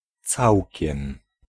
t͡s[3] c
całkiem cats